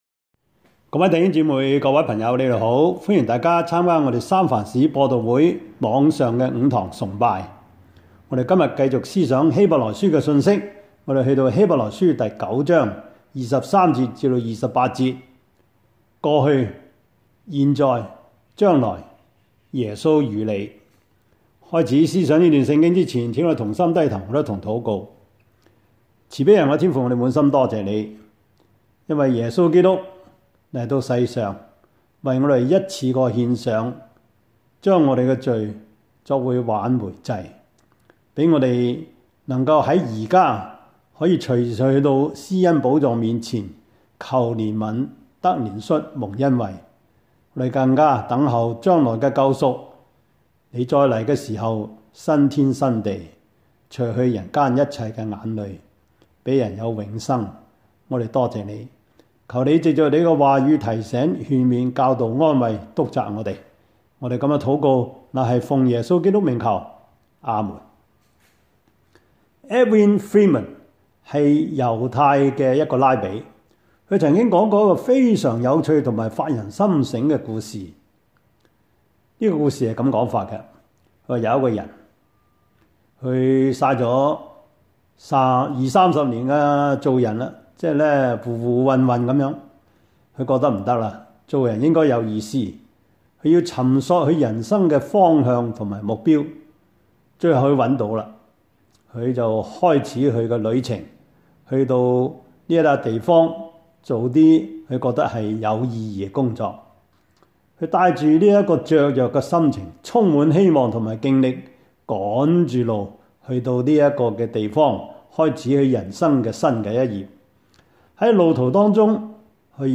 Service Type: 主日崇拜
Topics: 主日證道 « 第十五課: 義和團之亂(1) 生命的奇蹟 »